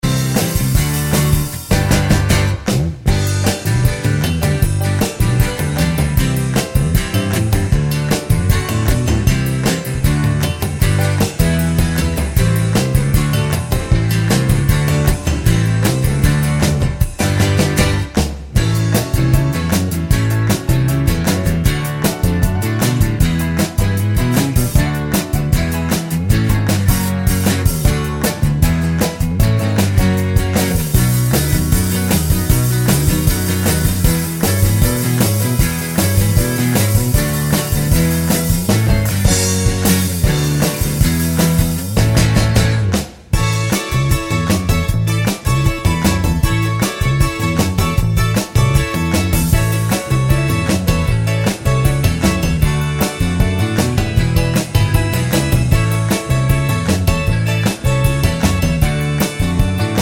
no Backing Vocals Rock 4:56 Buy £1.50